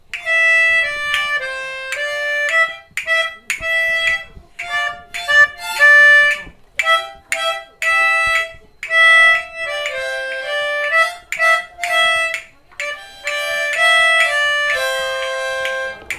합주.mp3